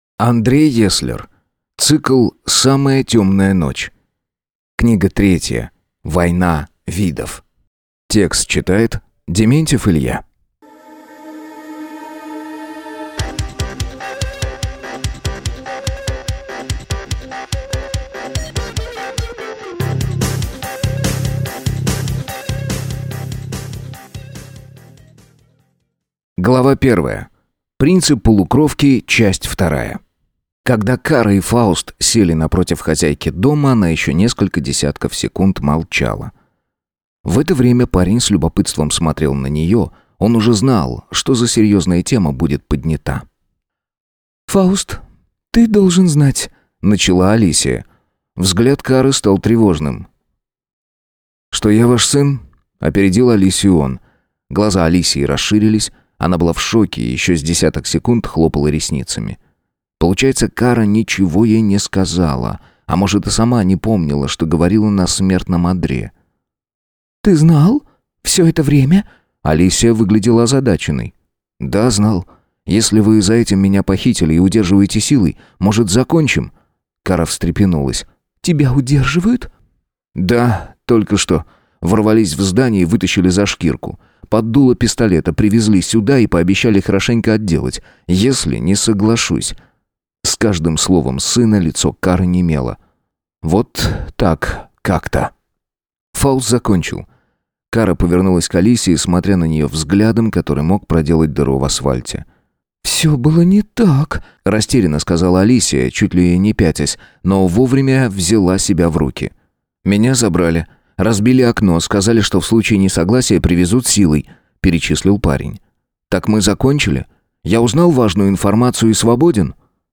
Аудиокнига Война видов | Библиотека аудиокниг